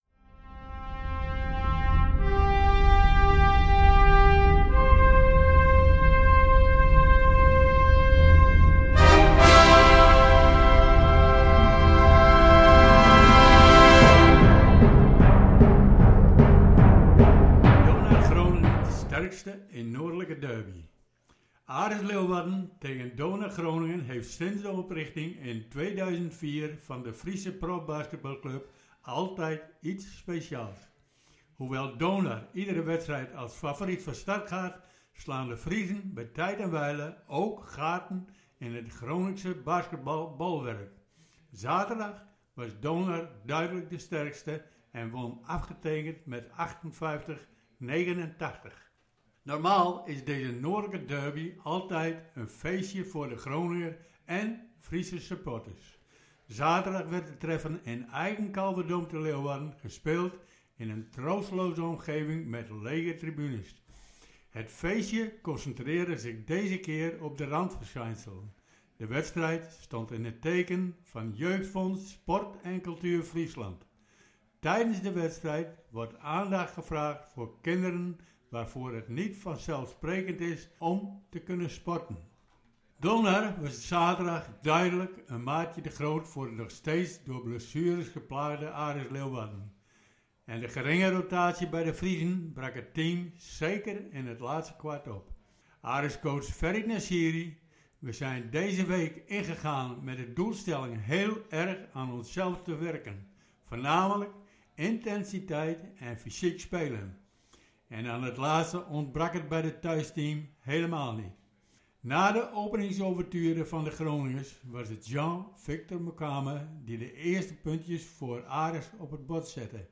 Interviews
Zaterdag werd het treffen in eigen Kalverdome te Leeuwarden gespeeld in een troosteloze omgeving met lege tribunes.